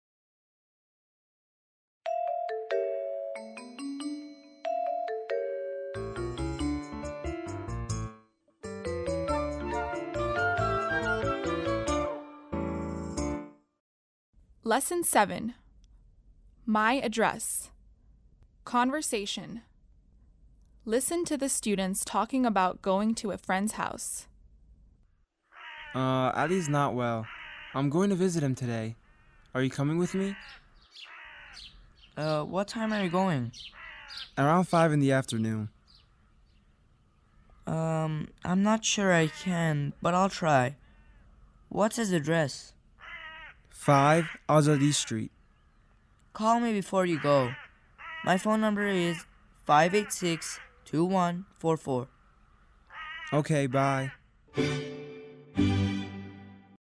7-Lesson7-Conversation.mp3